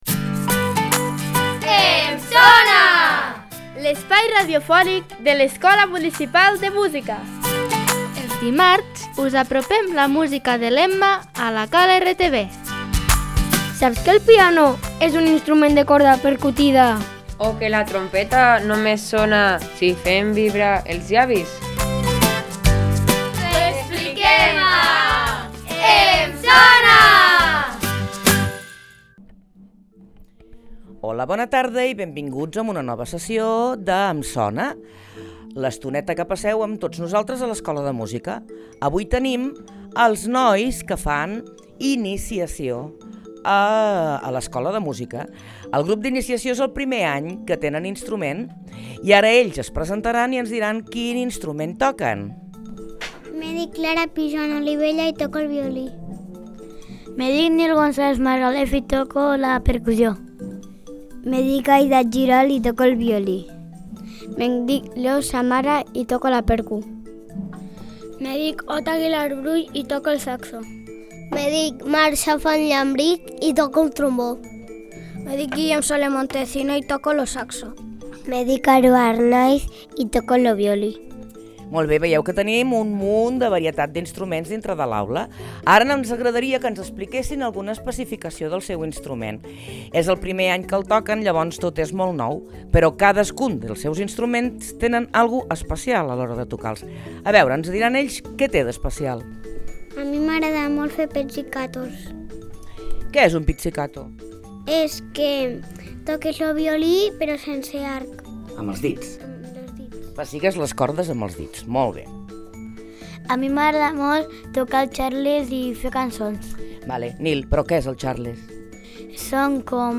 Gènere: Kids, Comedy, Soundtrack.